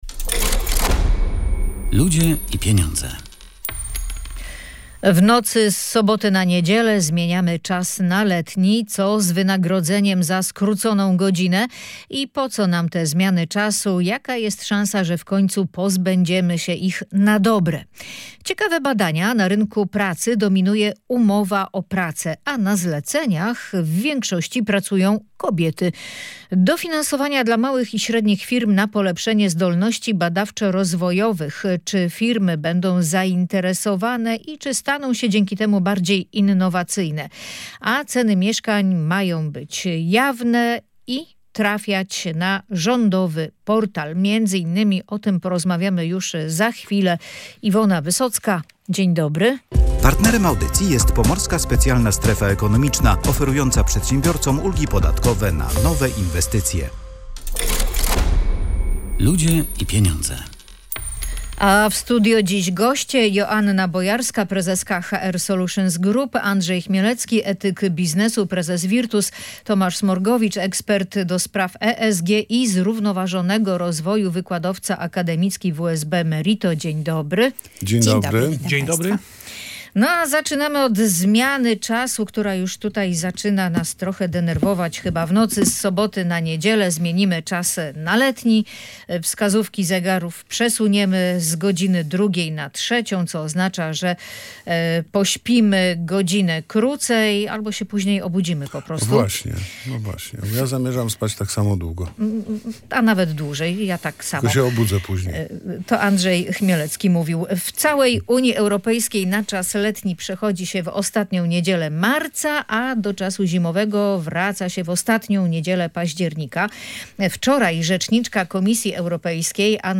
Ekonomiści w audycji „Ludzie i Pieniądze” jasno wyrazili swoje krytyczne zdanie na ten temat. Według nich generuje to dodatkowe koszty i nie jest dla nas dobre.